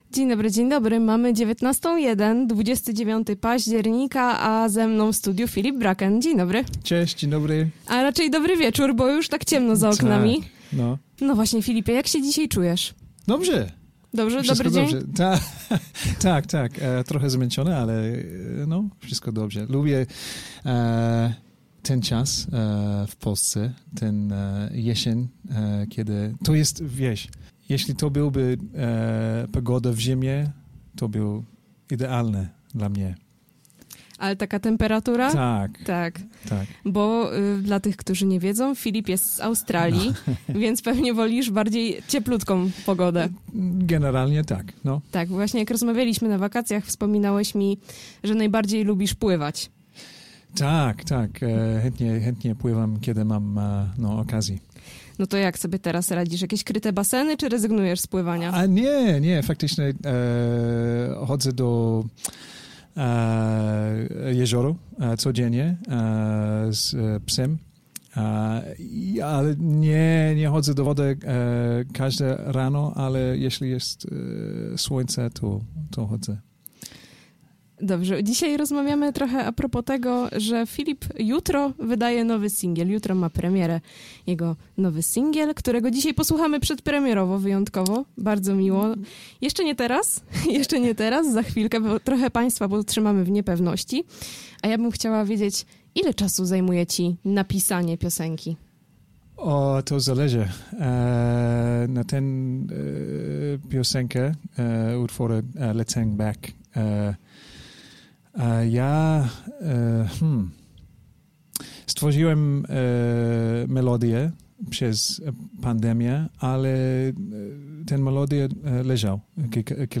wywiad z artystą